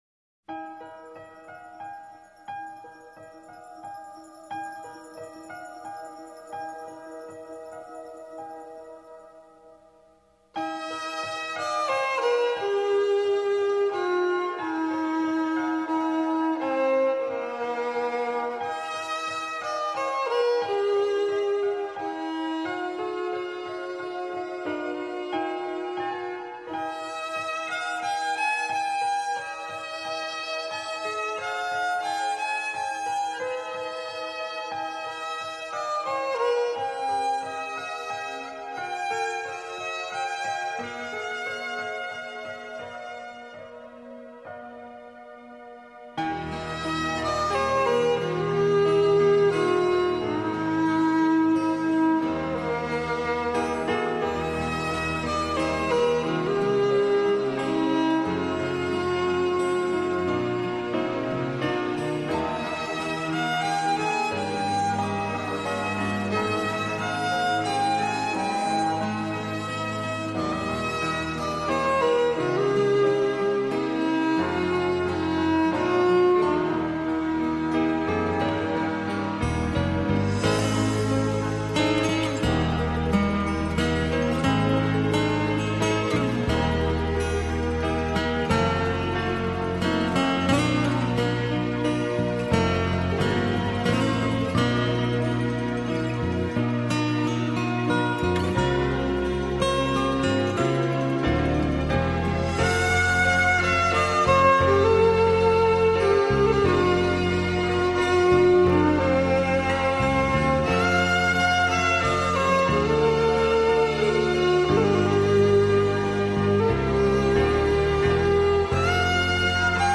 小提琴专辑精选】新世纪音乐
完全的惊喜，完全可以让大家耳目一新的感觉，尤其表现在火爆之小提琴